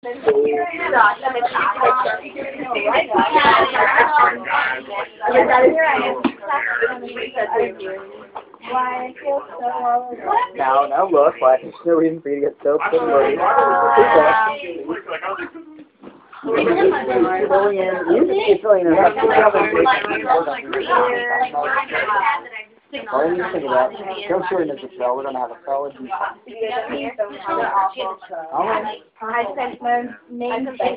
Location: Drama Lounge in Emily Lowe
Sounds heard: A cacophony of everyone talking at once